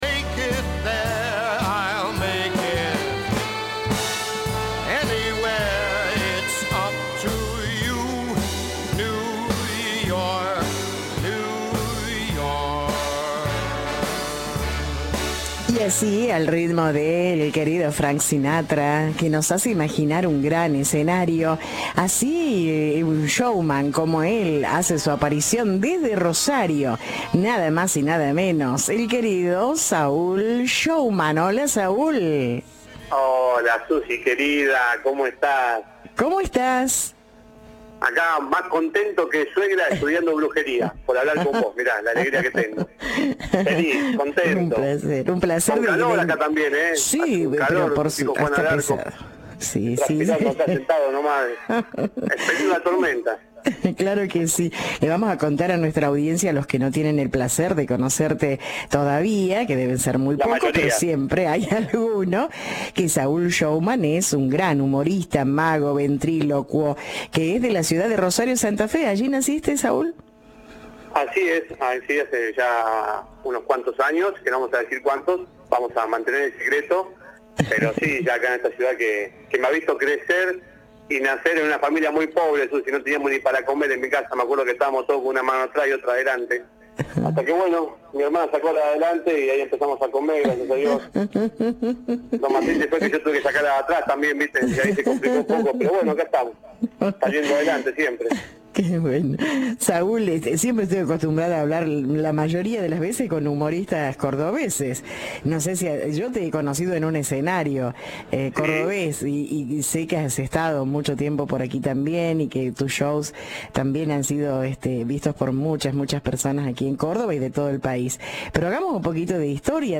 Volvé a escuchar la entrevista en Noche y Día.